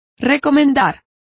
Complete with pronunciation of the translation of commend.